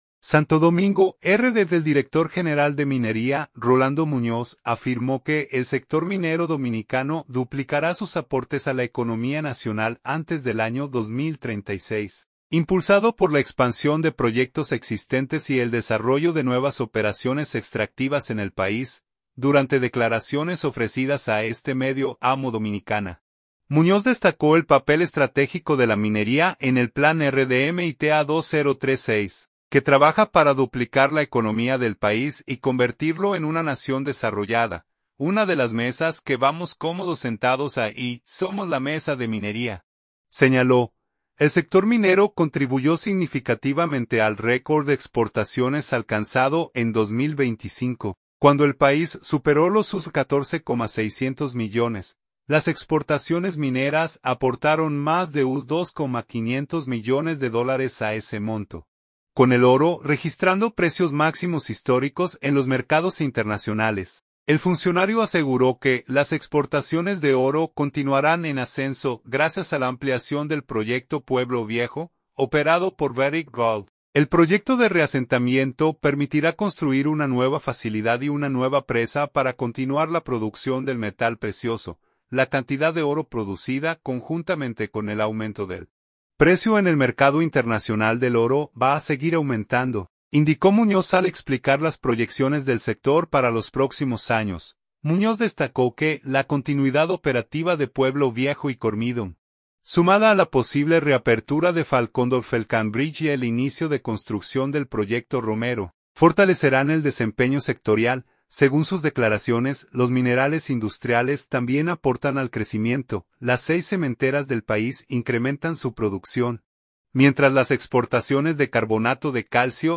Las declaraciones del funcionario se produjeron durante el lanzamiento del programa «Educando para el futuro de una minería responsable», una iniciativa de la Dirección General de Minería dirigida a estudiantes y comunidades educativas.